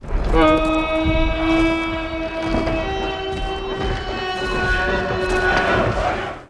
Horn of the Haradrim
hardhrim_horn.wav